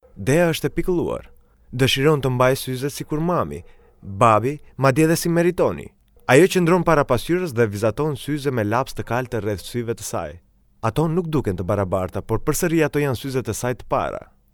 Arnavutça Seslendirme
Erkek Ses